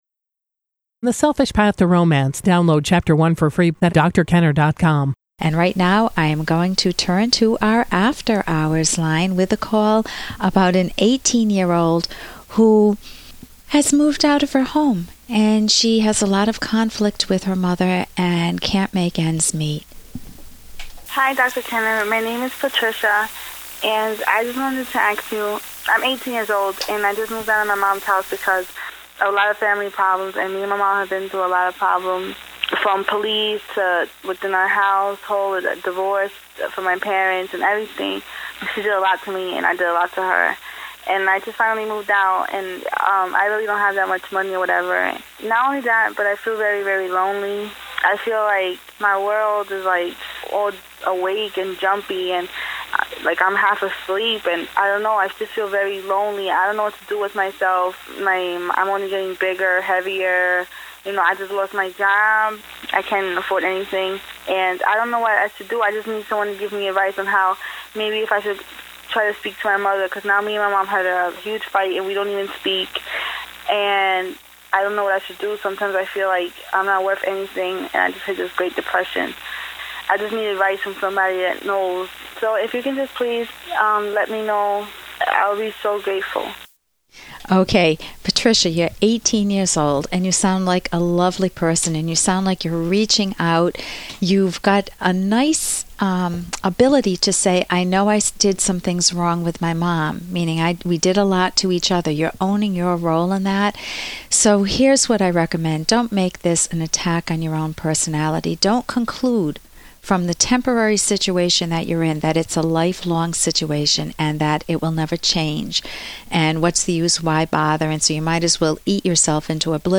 Family Friction ~ I fight with my mom and left home. Listen to caller's personal dramas four times each week